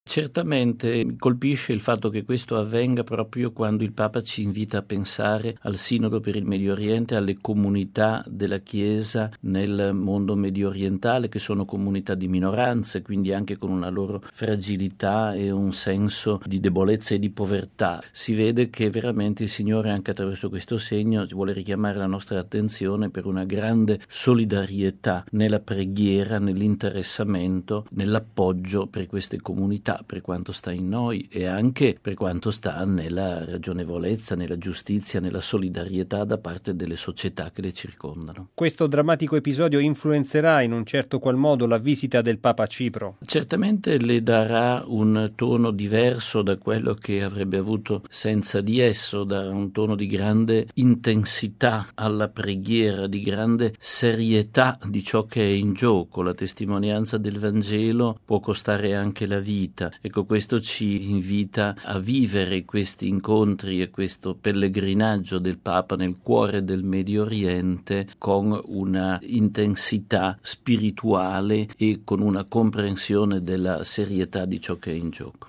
La drammatica notizia della morte del vicario apostolico in Anatolia ha raggiunto Benedetto XVI e i suoi collaboratori alla vigilia della partenza per Cipro. Una circostanza sula quale si sofferma il direttore della Sala Stampa della Santa Sede, padre Federico Lombardi: